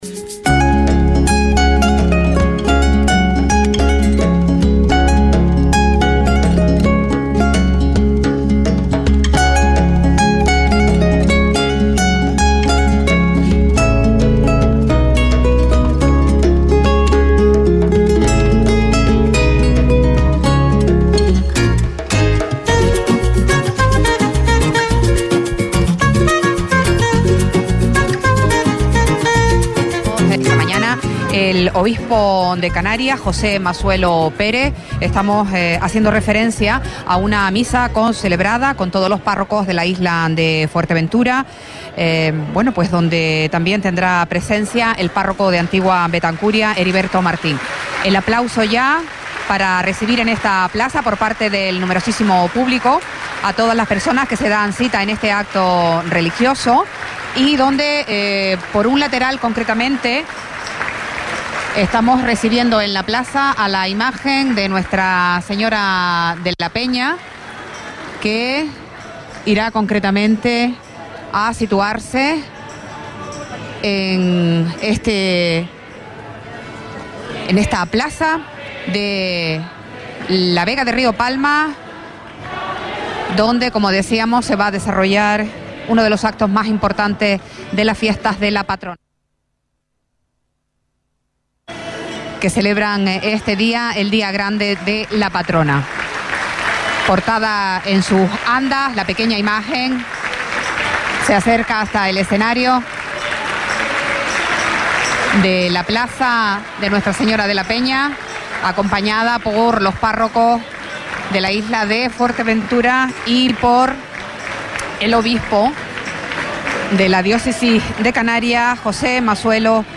Eucaristía en Honor a Nuestra Señora de La Peña 2023 - Radio Sintonía